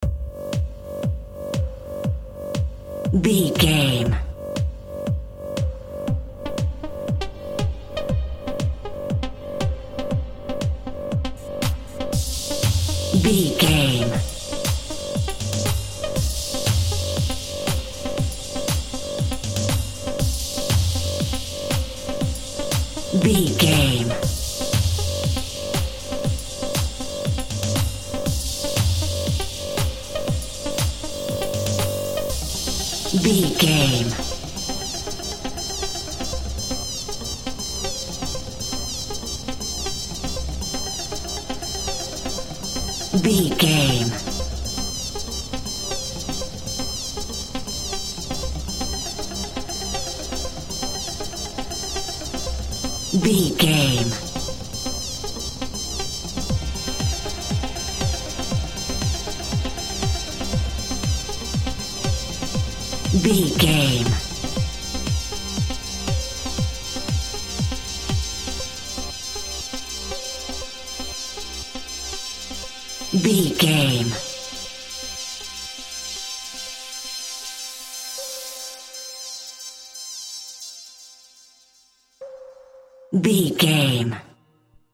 Modern Pop Top 40 Electronic Dance Music 60 Sec.
Fast paced
Aeolian/Minor
Fast
dark
futuristic
epic
groovy
aggressive
repetitive
synthesiser
drums
drum machine
house
electro dance
techno
trance
synth leads
synth bass
upbeat